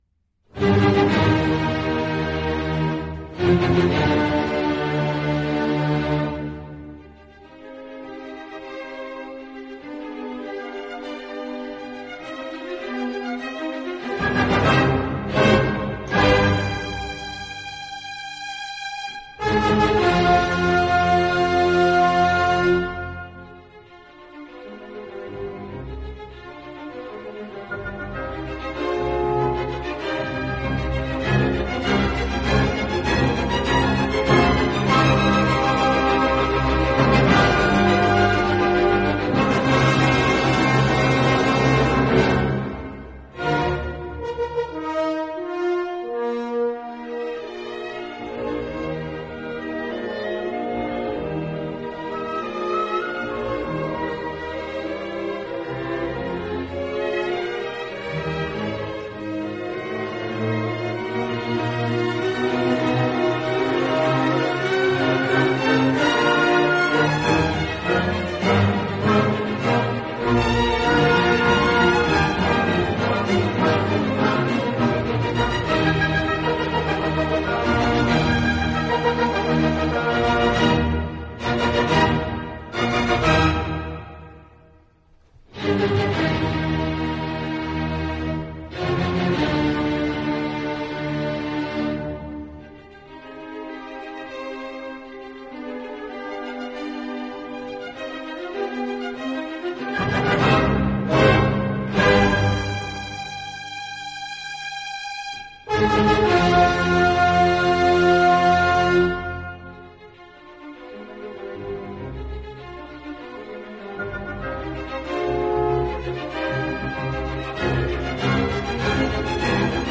So I have lined up for you 7 of Beethoven’s best known and most loved works and we will listen to these without interruption for this hour.
Performed by the Berliner Philharmoniker conducted by Herbert von Karajan